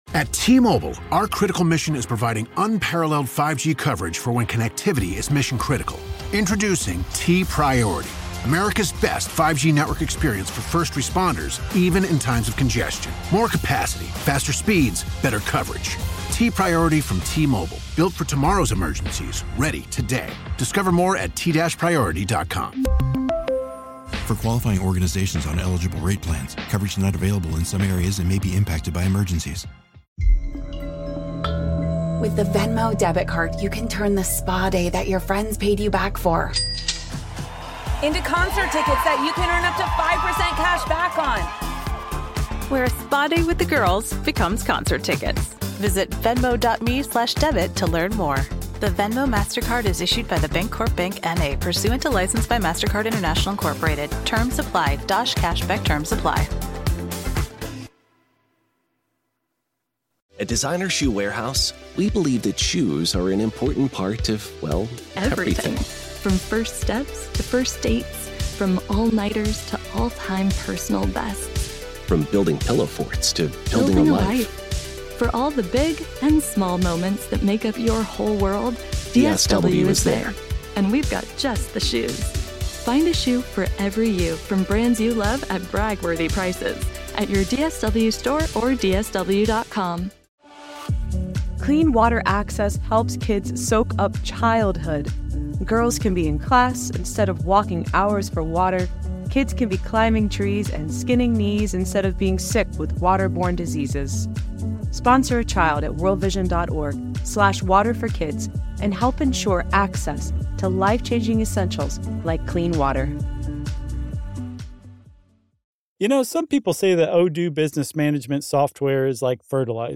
While he passed away in 2002, his epic storytelling can now be heard here on Our American Stories, thanks to the efforts of those who manage his estate.